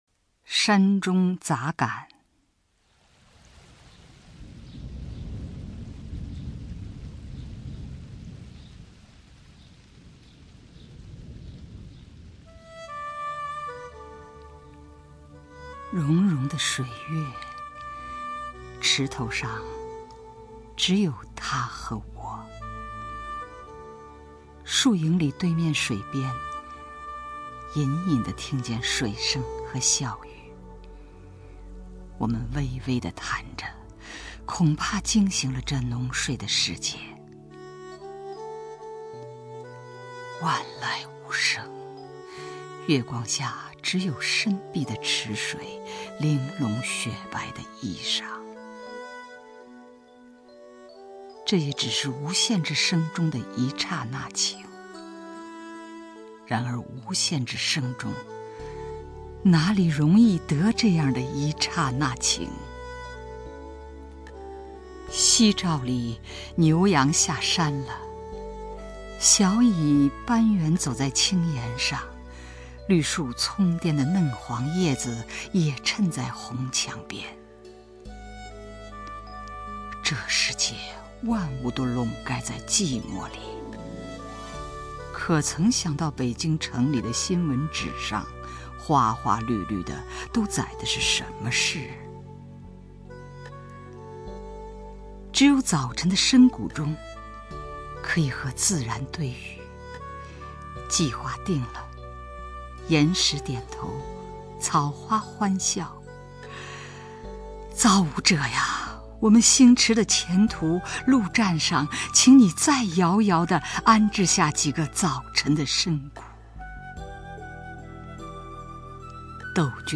吕中朗诵：《山中杂感》(冰心)